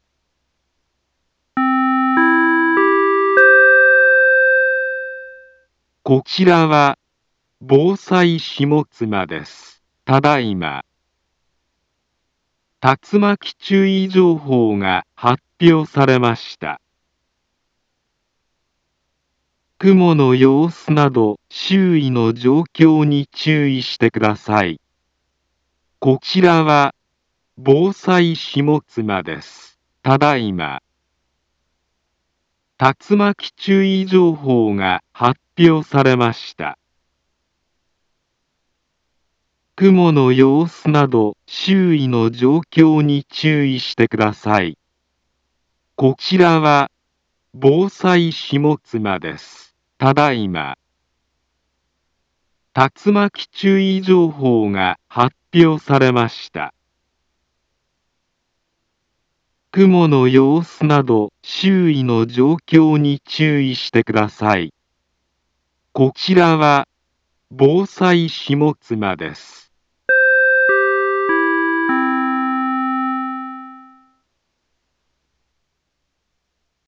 Back Home Ｊアラート情報 音声放送 再生 災害情報 カテゴリ：J-ALERT 登録日時：2022-08-03 15:15:29 インフォメーション：茨城県北部、南部は、竜巻などの激しい突風が発生しやすい気象状況になっています。